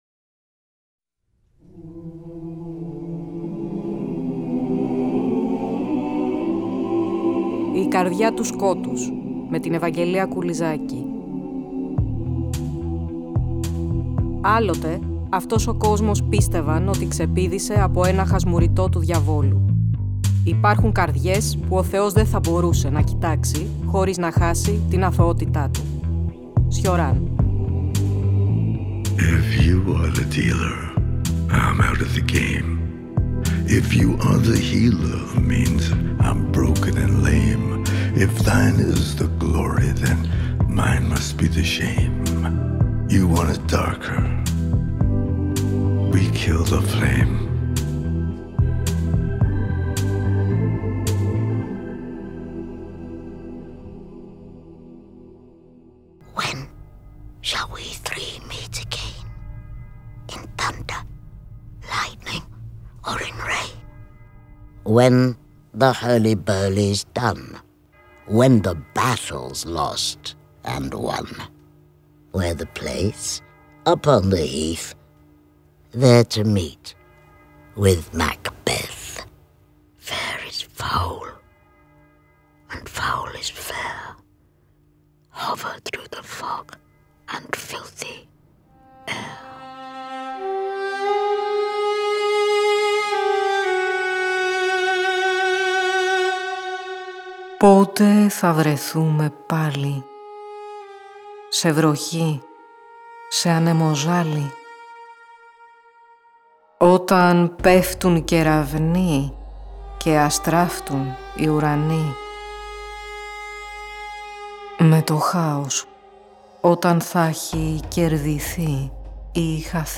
Στην εκπομπή της Κυριακής 23.11.2025, Η ΚΑΡΔΙΑ ΤΟΥ ΣΚΟΤΟΥΣ εμπλουτίζοντας τη δέσμη εκπομπών για την Ιστορία της Μαγείας, παρεμβάλλει τη 2η ενότητα-αφιέρωμα στη Μαγεία και τη Λογοτεχνία, μ’ ένα ραδιοφωνικό ημίωρο για το περίφημο “scottish play”: διαβάζουμε το αριστουργηματικό, κρυπτικό δοκίμιο του Μαλλαρμέ για την Ψευδή Είσοδο των Μαγισσών στον Σαιξπηρικό «Μάκβεθ» και, σε αποκλειστική μετάδοση, αποσπάσματα από τον «Μάκβεθ» σε ανέκδοτη μετάφραση του Διονύση Καψάλη.